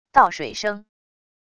倒水声wav音频